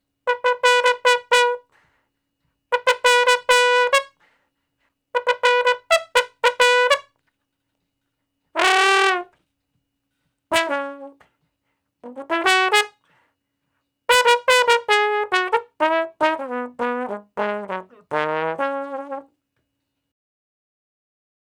099 Bone Straight (Db) 05.wav